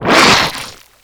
combat
vacushit1.wav